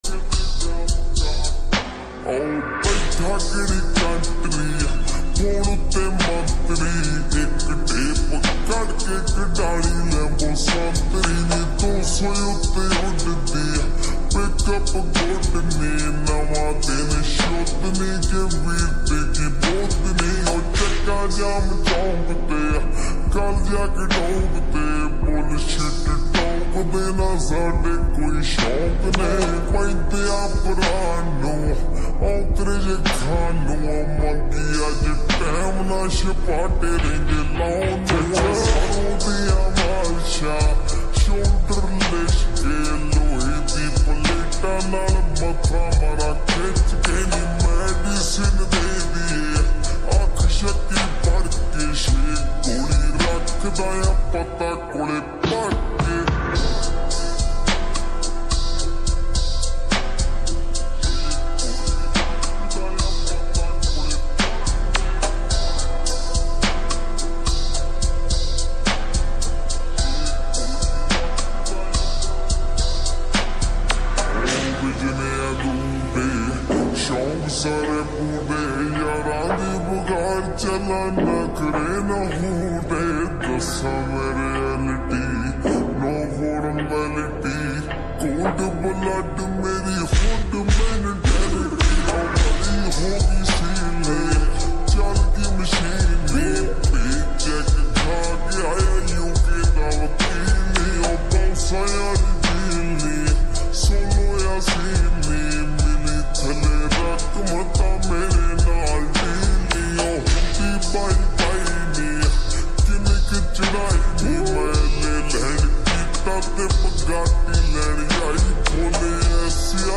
𝙎𝙊𝙇𝙑𝙀𝘿 𝙍𝙀𝙑𝙀𝙍𝘽